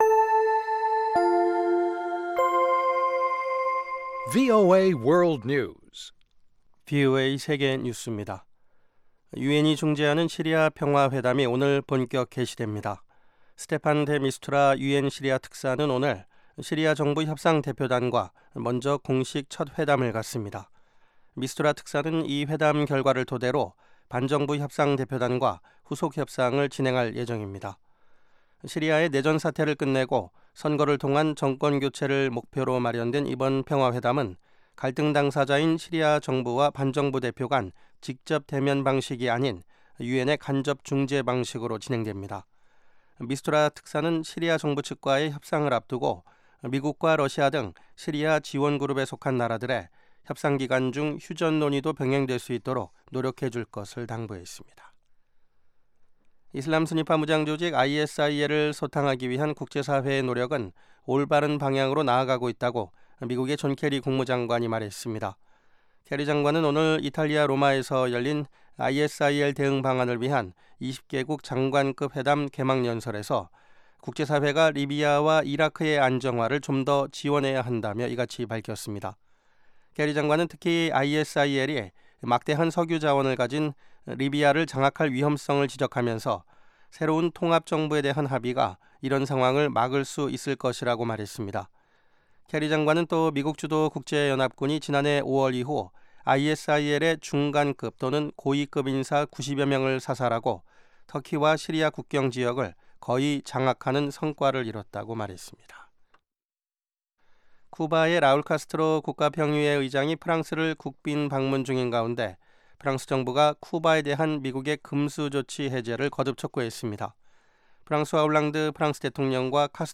VOA 한국어 방송의 간판 뉴스 프로그램 '뉴스 투데이' 2부입니다. 한반도 시간 매일 오후 9:00 부터 10:00 까지, 평양시 오후 8:30 부터 9:30 까지 방송됩니다.